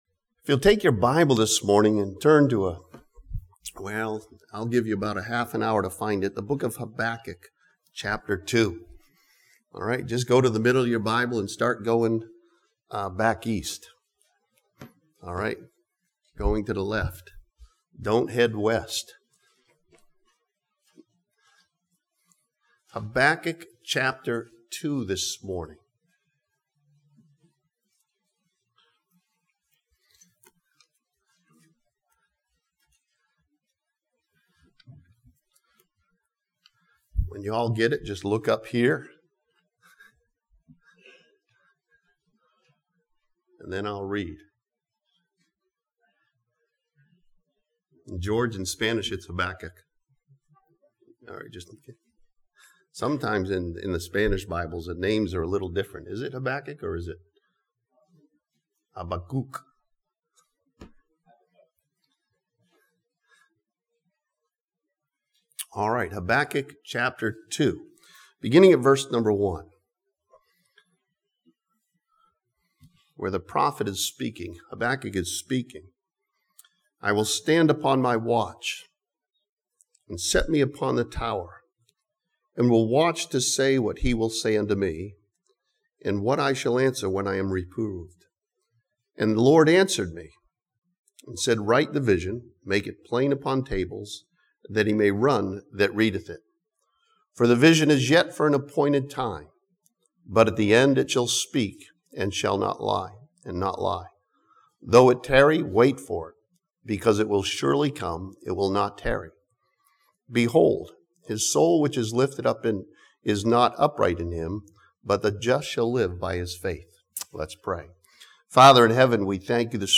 This sermon from Habakkuk chapter 2 challenges believers to have a personal faith in Jesus Christ.